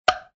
tick_counter.ogg